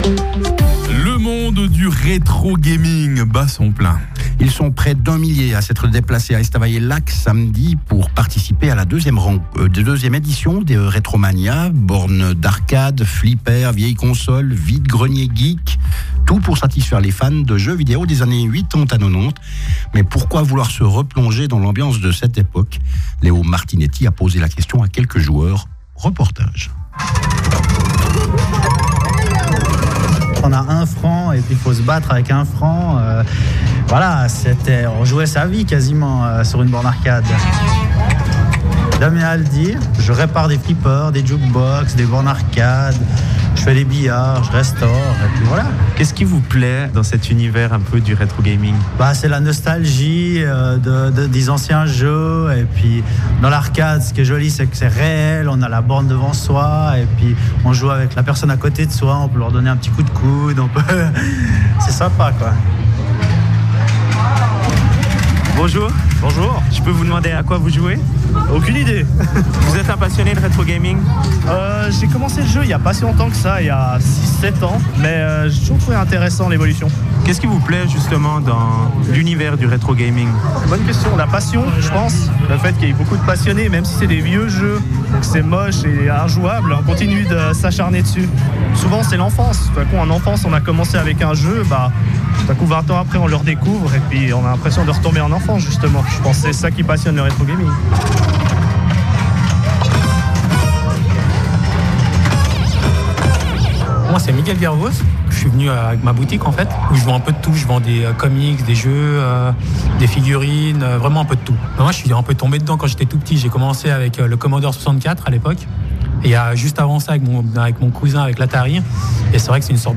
Reportage Radio Fribourg 2019
Reportage-Radio-Fribourg-2019_RetroMania.mp3